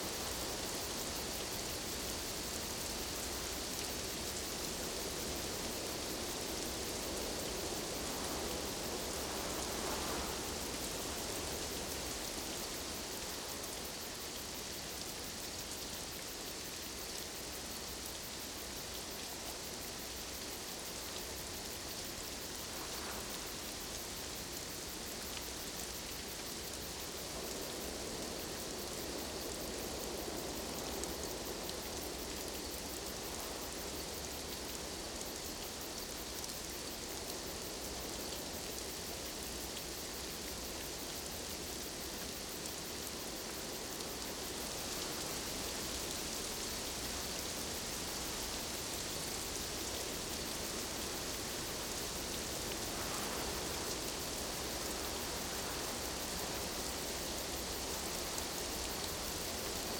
Forest Night Rain.ogg